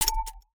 Universal UI SFX / Clicks
UIClick_Soft Tonal 03.wav